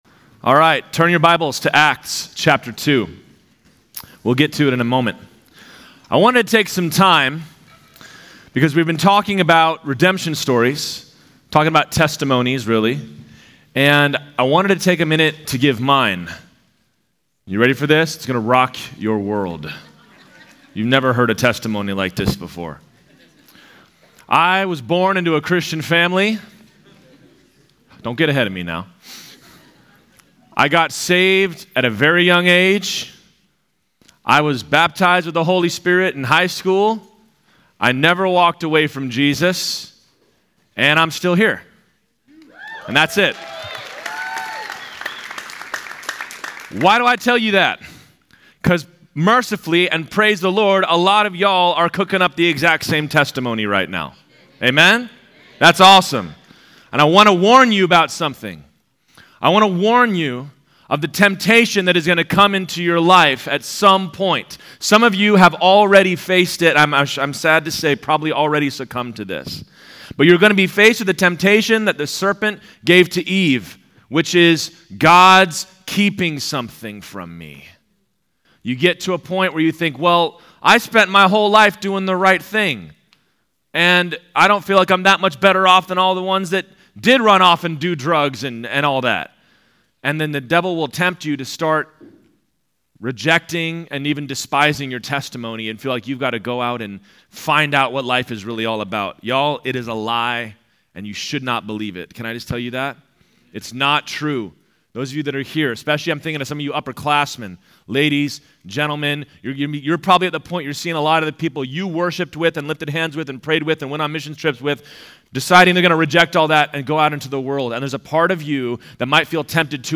Home » Sermons » Redemption Stories: Peter